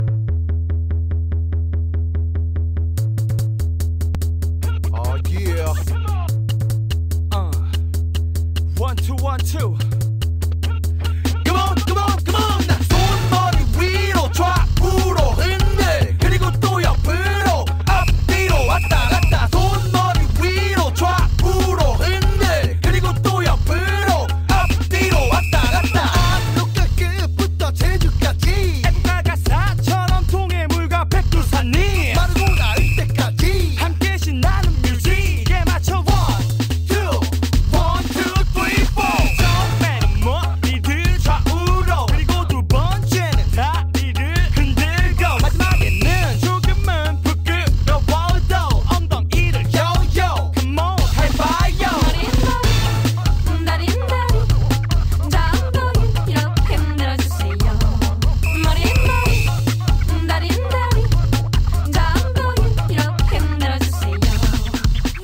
BPM145-145
Audio QualityCut From Video